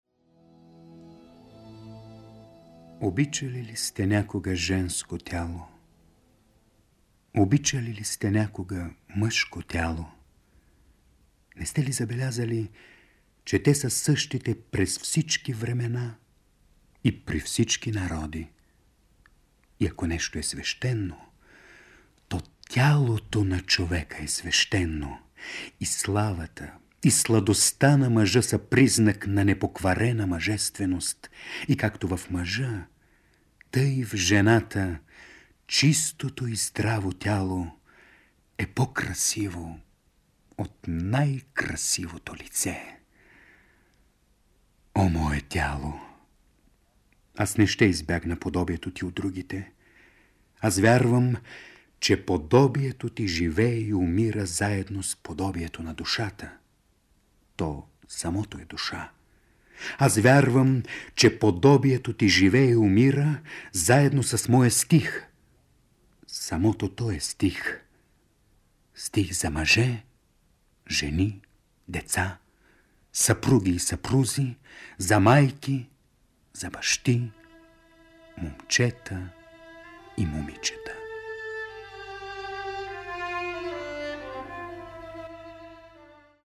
„Понякога, когато някого обичам…“ — думи, които достигат до слушателите на БНР през 1970 година с гласа на Сава Хашъмов. В стиховете си Уолт Уитман описва блажения култ към свещеното женско, мъжко и детско тяло, като съвършено творение на природата.